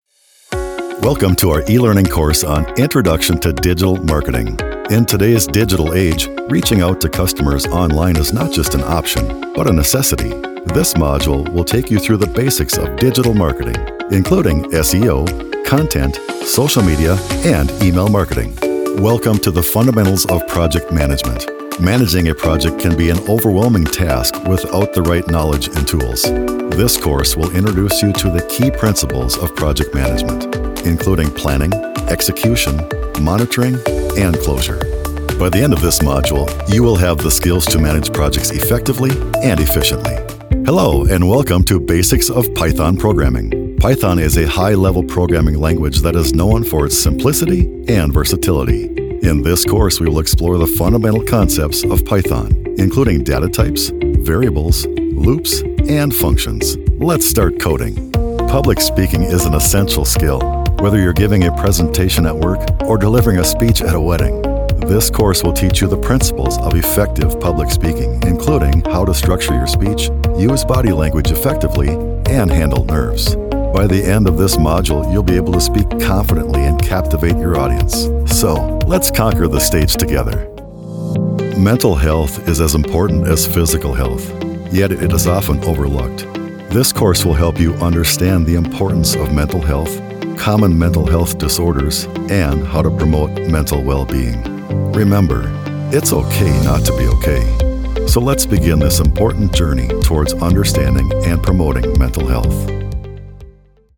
Middle Aged
With his natural and rich bass, he effortlessly shifts from a conversational and engaging style to a powerful promo style.